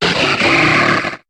Cri de Colhomard dans Pokémon HOME.